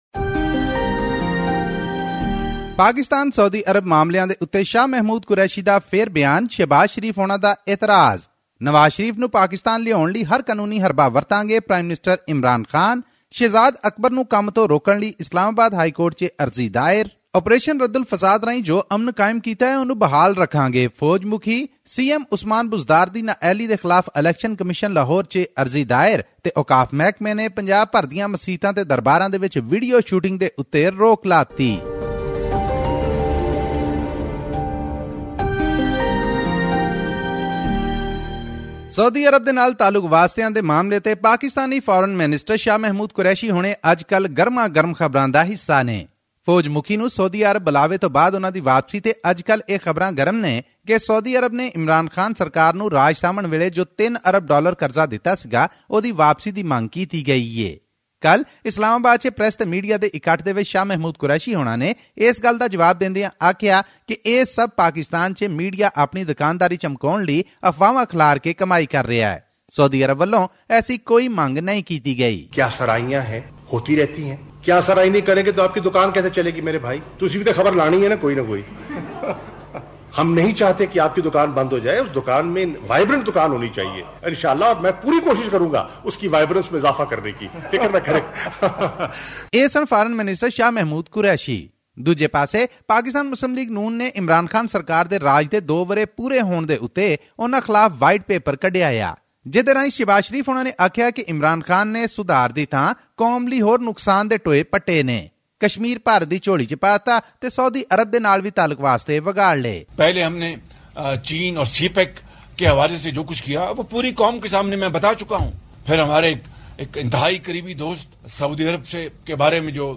Another statement on Saudi Arabia by Shah Mahmood Qureshi has annoyed Shahbaz Sharif. This and much more in our weekly report from Pakistan.